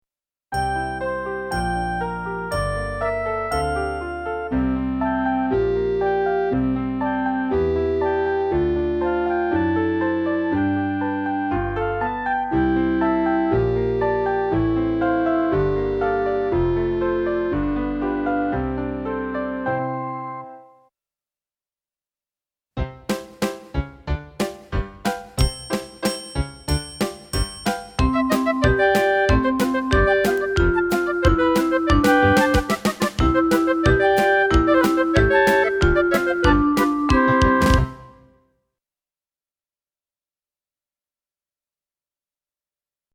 Extra soundmixbegeleidingen voor tutors instrument: Oefening 1 -